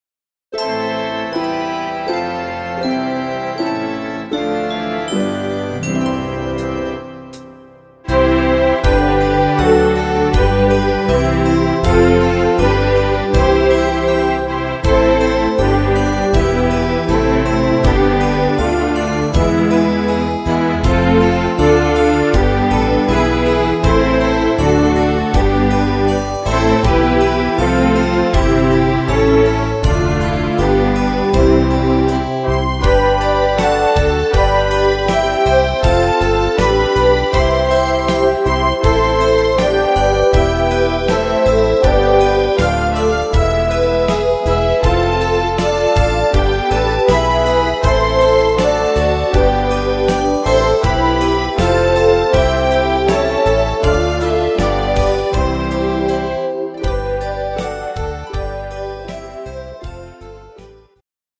Rhythmus  Ballade
Art  Traditionell, Deutsch, Weihnachtslieder